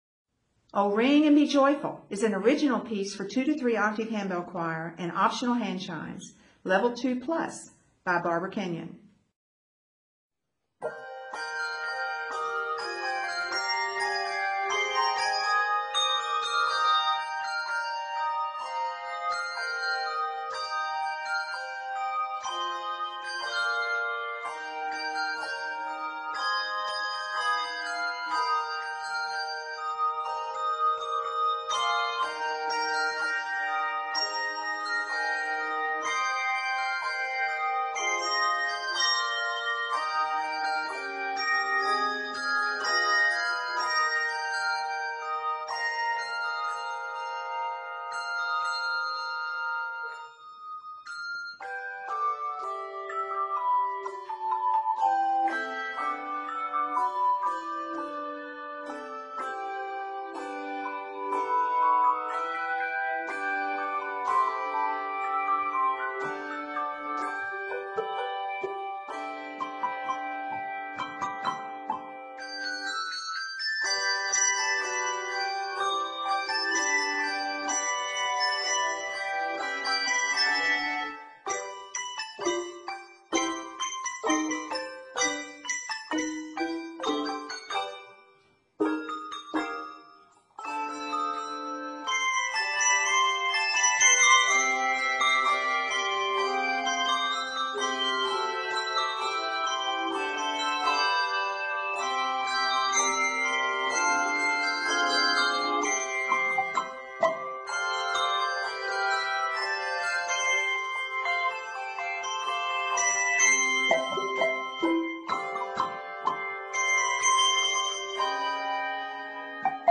exciting and energetic original piece
2-3 octave handbell choir with optional handchimes